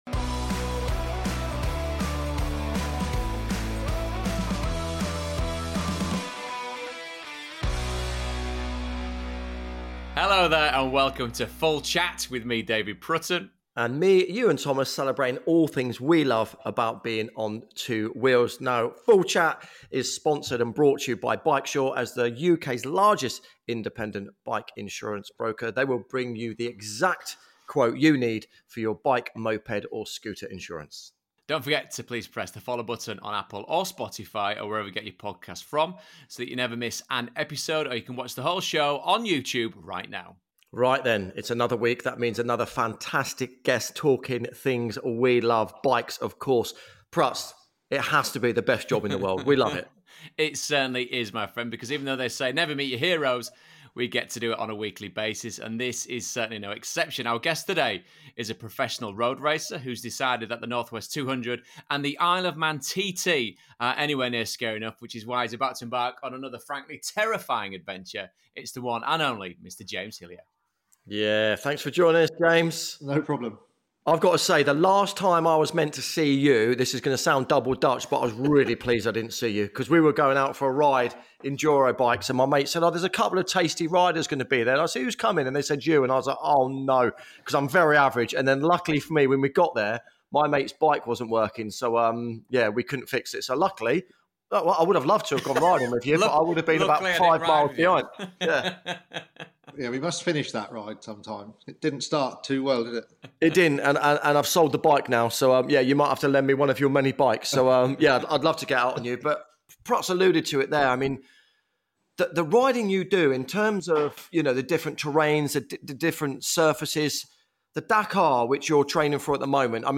catch up with him at home in Dorset